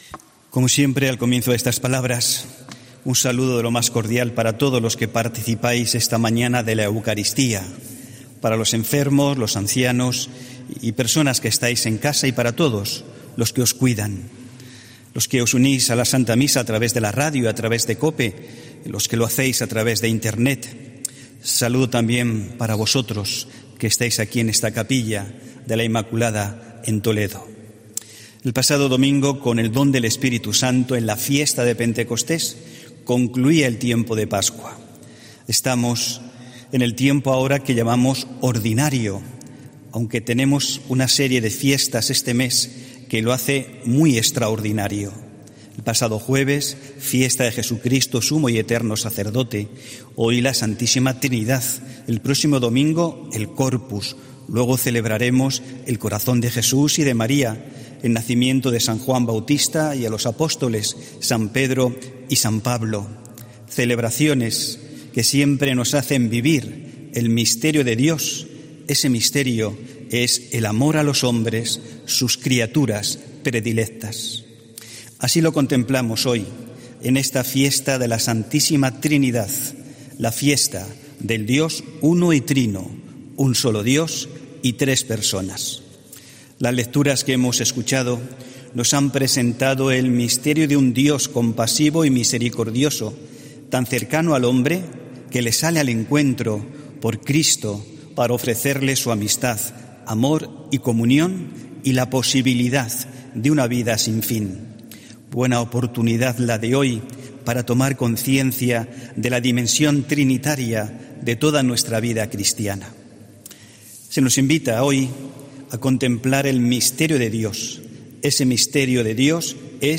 HOMILÍA 7 JUNIO 2020